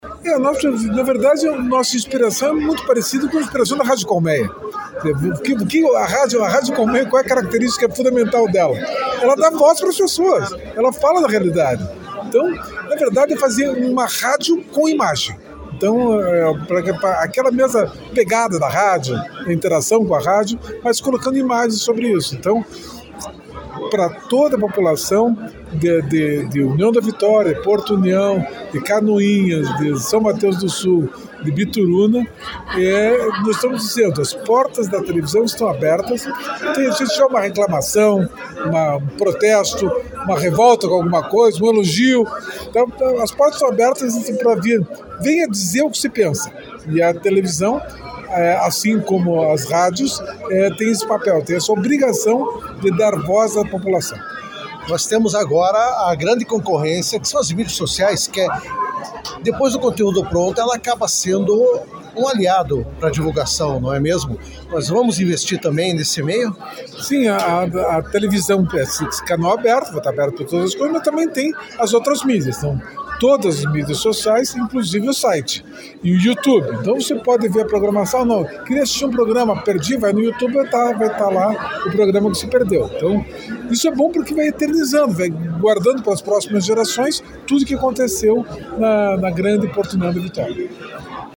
Na noite desta quinta-feira (14), a TV UBPLAY inaugurou oficialmente seu novo e moderno prédio em União da Vitória.